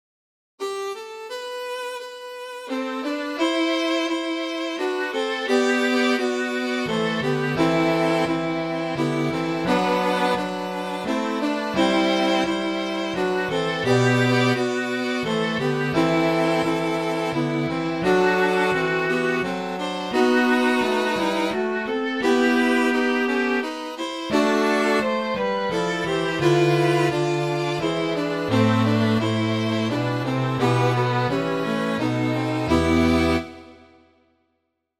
Vierstimmiges Arrangement.